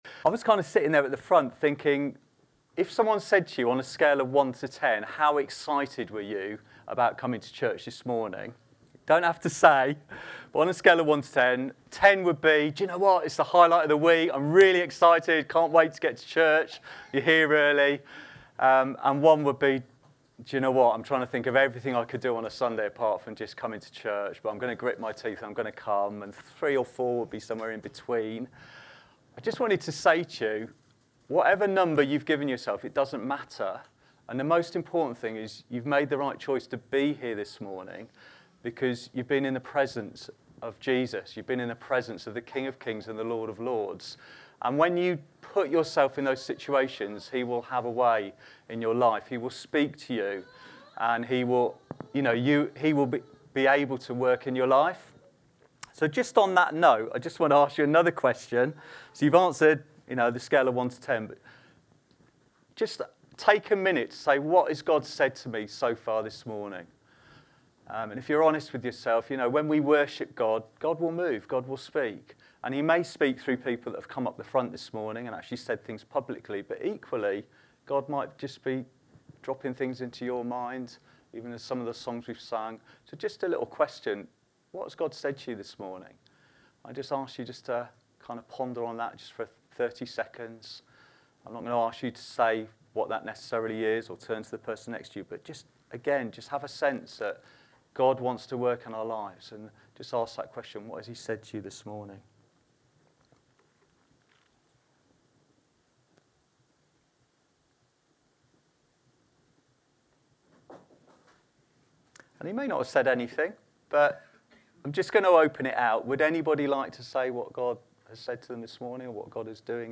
Download Facilitating Gifts of the Spirit | Sermons at Trinity Church